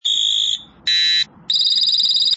AC9KS03是一颗标准的高分贝三声电子口哨声音效芯片，频率音音效，声音更清脆，常用于电子口哨，警报器，产品警报提醒，无需震荡电阻，三种常用电子口哨音效可选。
电子口哨三声报警下载1,仅供参考。
声音内容：电子口哨音效，上电长响
AC9WhistleWav.mp3